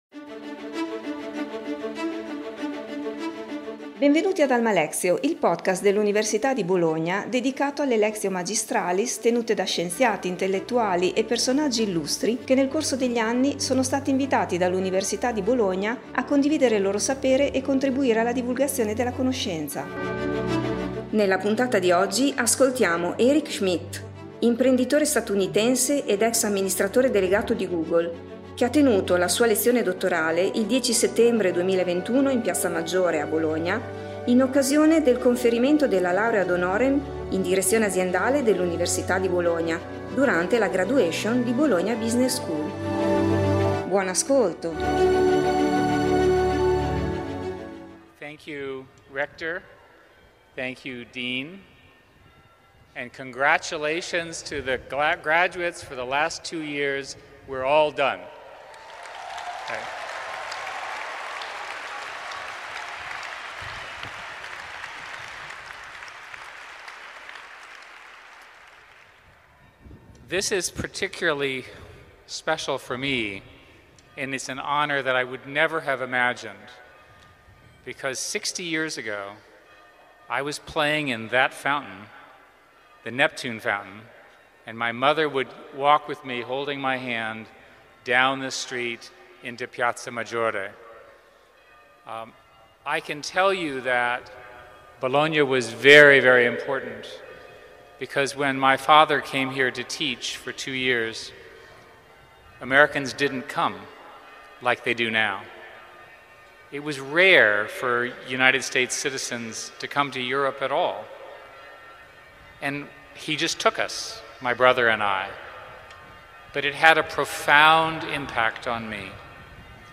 Eric Schmidt, imprenditore statunitense ed ex amministratore delegato di Google, ha tenuto la sua lezione dottorale il 10 settembre 2021 in Piazza Maggiore a Bologna in occasione del conferimento della Laurea ad honorem in Direzione Aziendale dell’Università di Bologna, durante la cerimonia di Graduation di Bologna Business School.